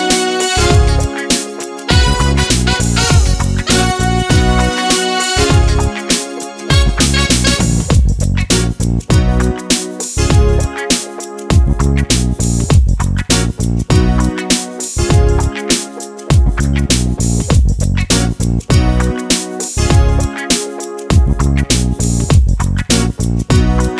no Backing Vocals R'n'B / Hip Hop 3:46 Buy £1.50